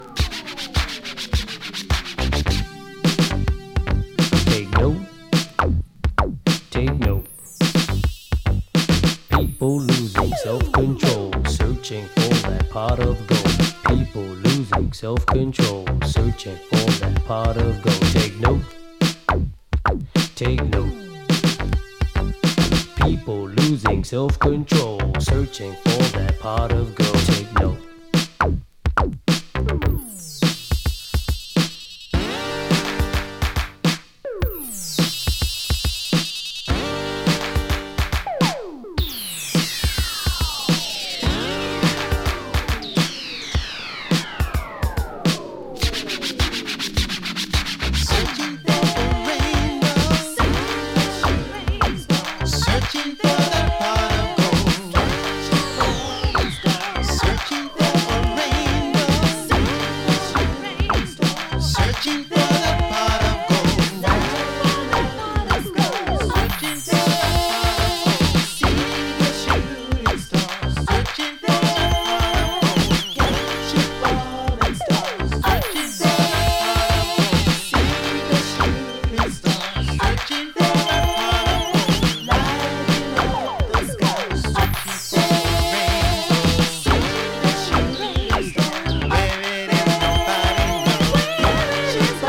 呟くようなRapと、素っ頓狂なシンセ、明るいサビの